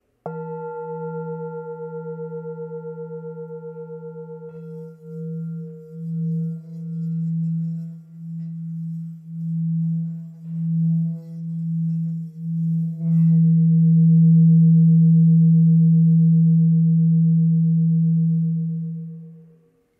Tibetská mísa Go střední
tibetska_misa_s23.mp3